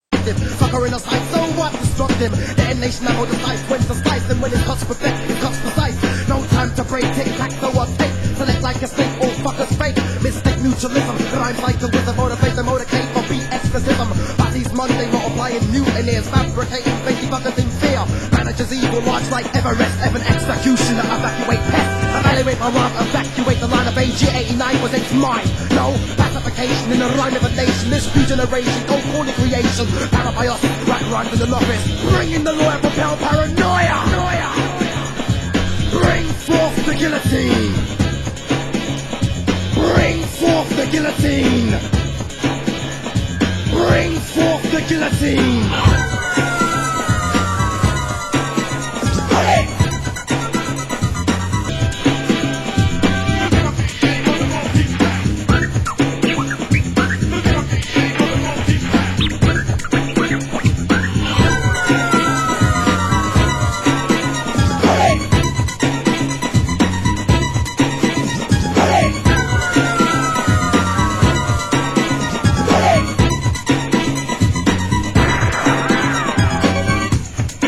Genre: Hip Hop